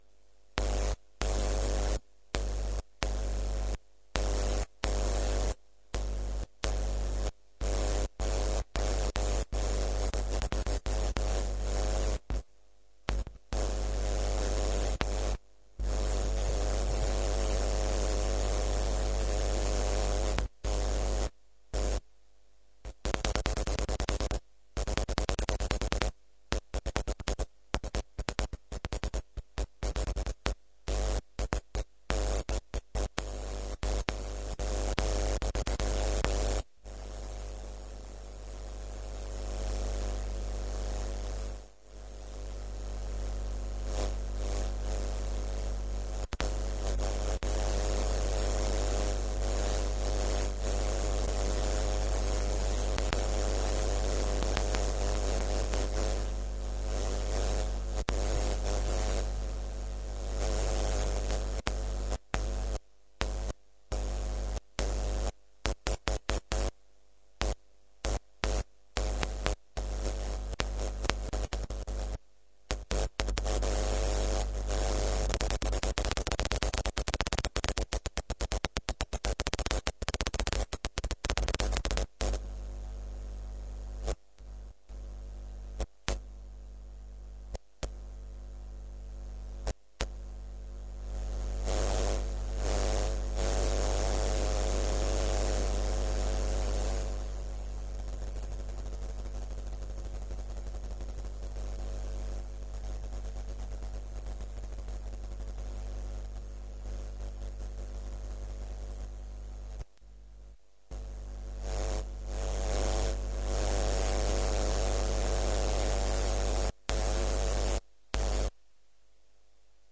舔电缆" 噪声 curt
Tag: 电缆 电气 电子 机械 噪声 信号